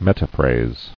[met·a·phrase]